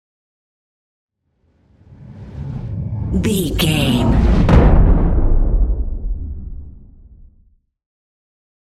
Cinematic whoosh to hit deep
Sound Effects
Atonal
dark
intense
tension
woosh to hit